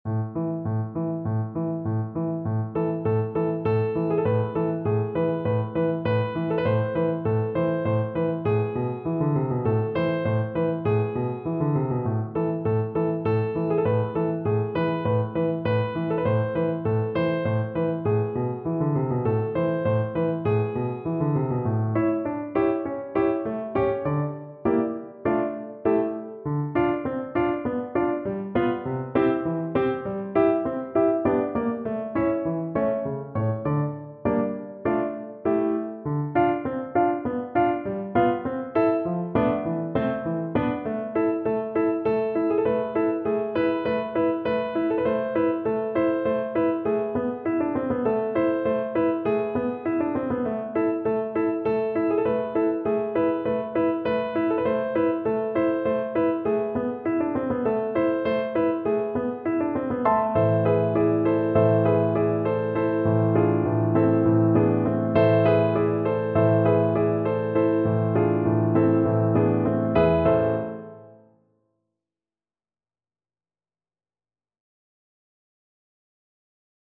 Allegro Moderato (View more music marked Allegro)
4/4 (View more 4/4 Music)
E2-D4
Classical (View more Classical Double Bass Music)